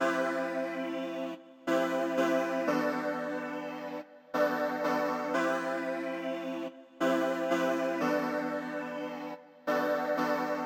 描述：松散型，80年代
Tag: 90 bpm Hip Hop Loops Synth Loops 1.79 MB wav Key : Unknown